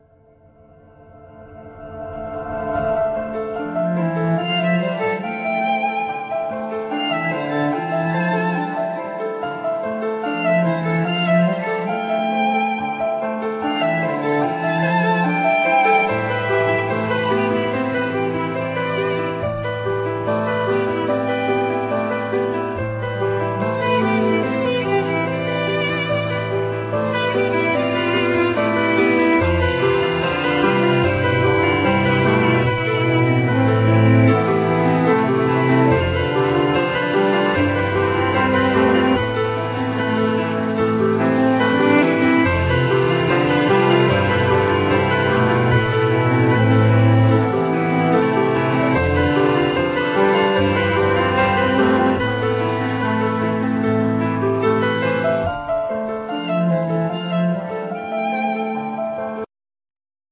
Piano
Voice
Violin